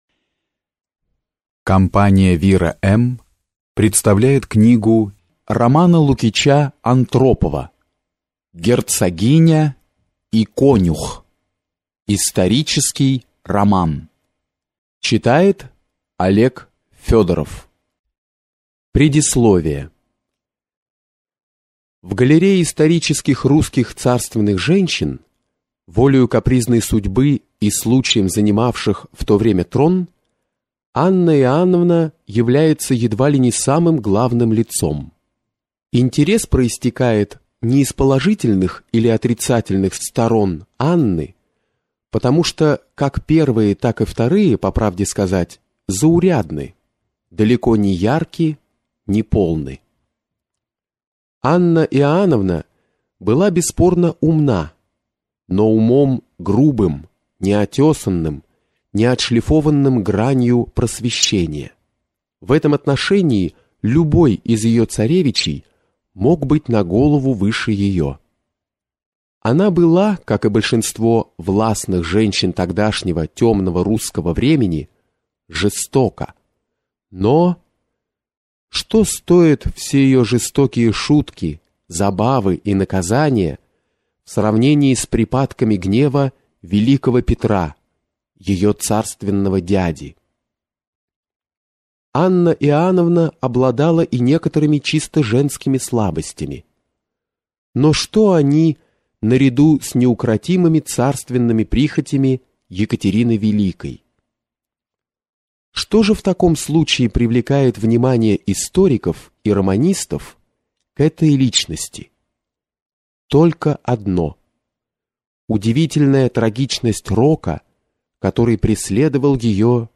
Аудиокнига Герцогиня и «конюх» | Библиотека аудиокниг